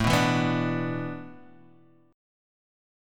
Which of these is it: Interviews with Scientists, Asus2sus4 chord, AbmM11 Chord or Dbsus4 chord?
Asus2sus4 chord